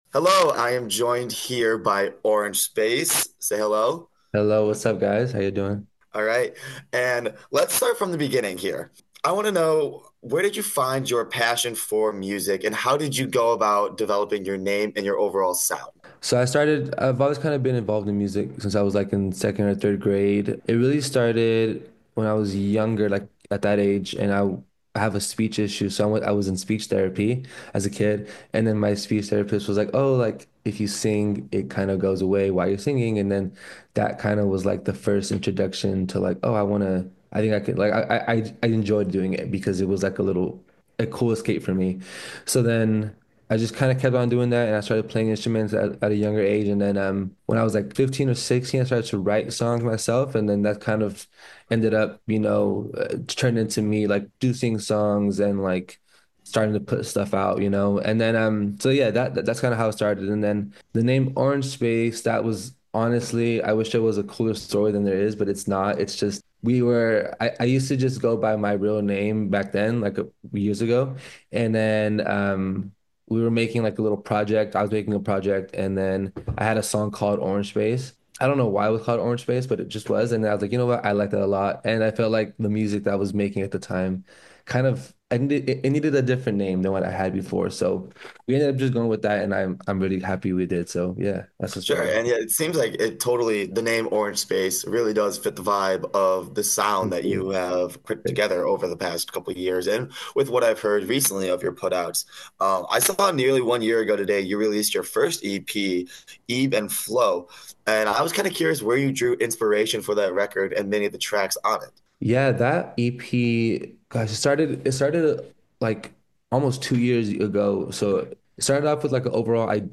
This week’s local artist featured on New Music Monday was Oranje Space. Click here to hear some highlights from the interview.